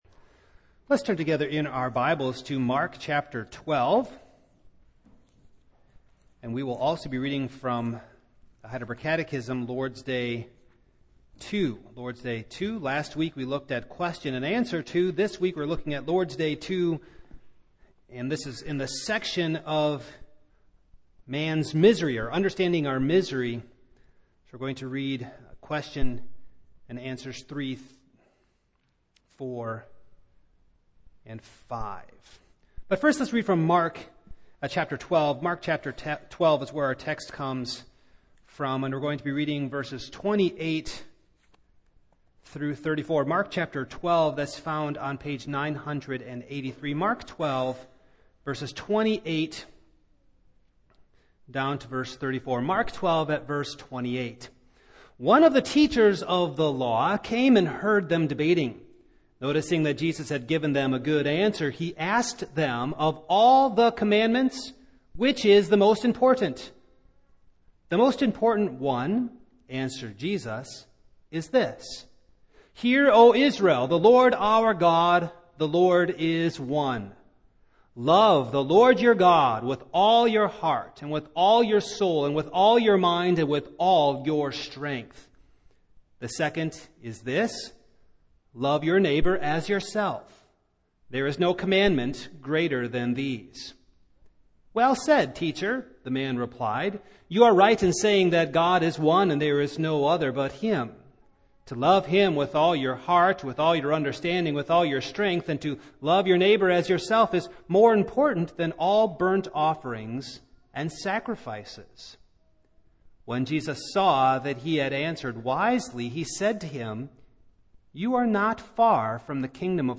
Passage: Mark 12:28-34 Service Type: Evening